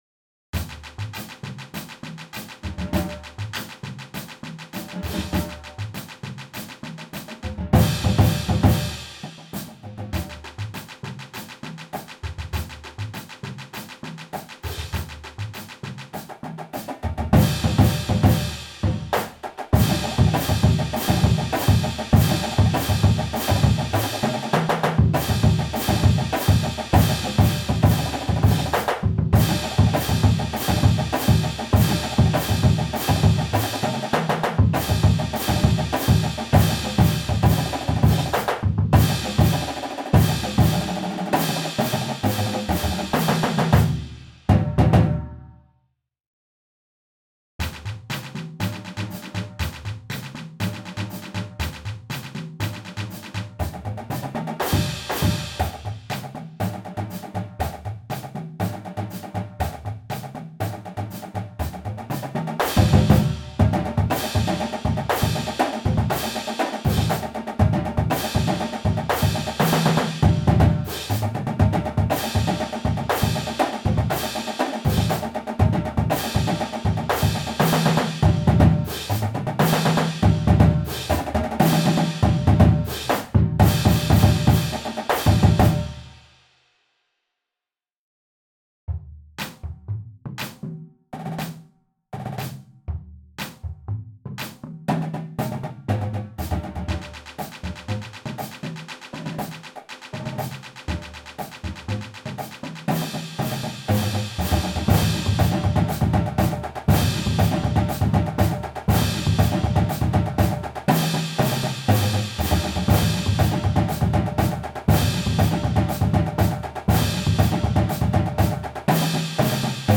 Voicing: Marching Percussion